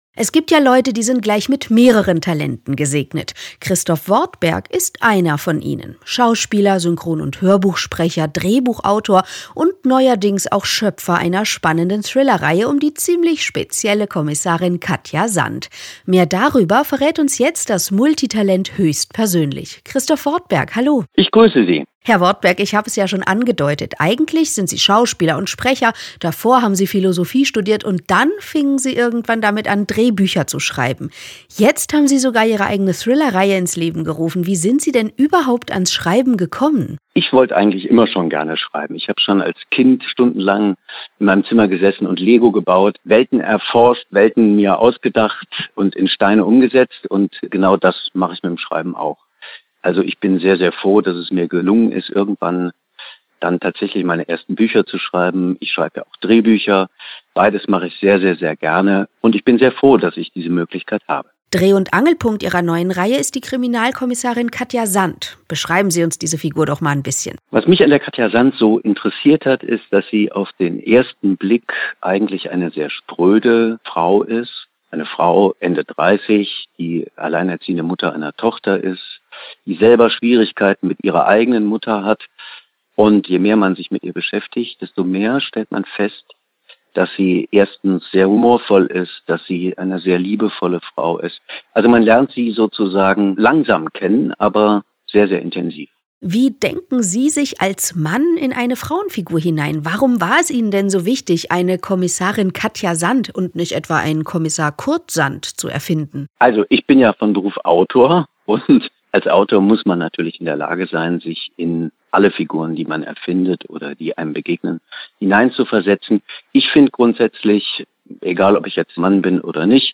Autorenlesung (MP3)